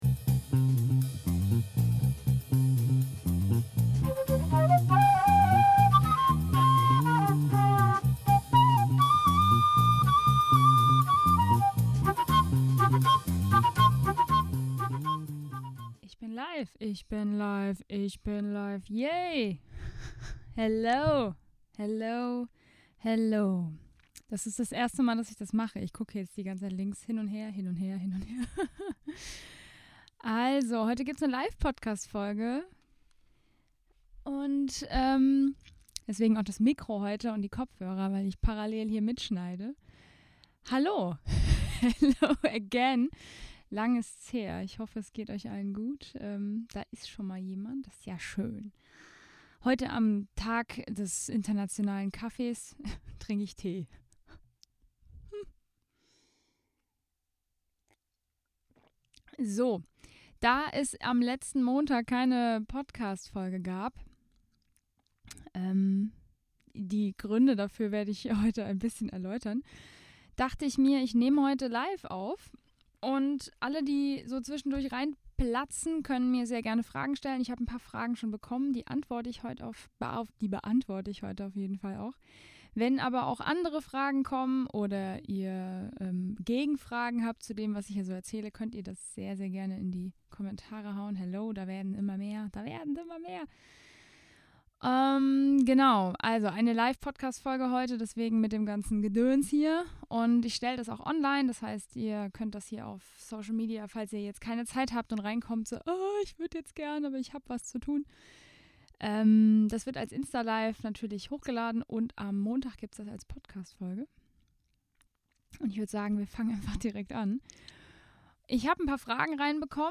Letzte Episode Live Podcastfolge vom 01.10.21 4. Oktober 2021 Nächste Episode download Beschreibung Teilen Abonnieren Ich war auf Instagram Live am 01.10.21 und habe das Instalive als Podcastfolge mitgeschnitten. Es ging um meinen Studienabschluss, mein Studium generell und was ich jetzt so treibe, wie es mit Managemusik weitergeht und warum Mentaltechniken so wichtig sind.